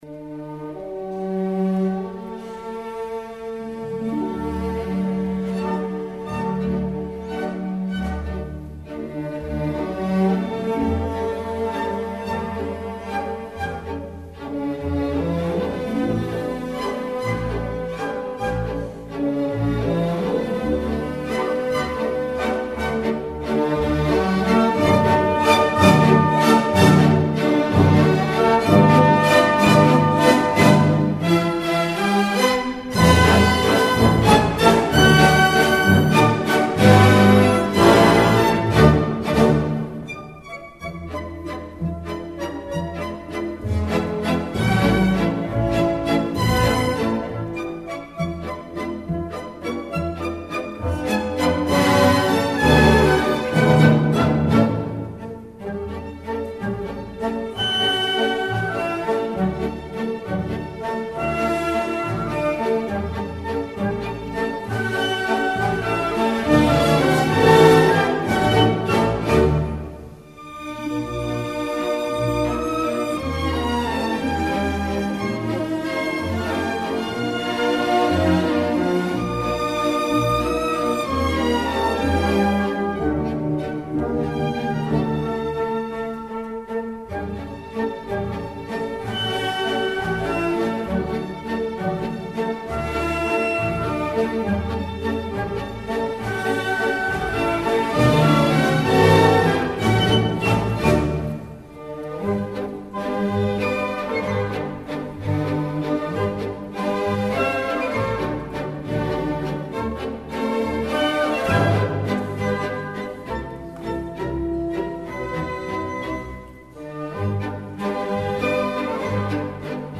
类型：classical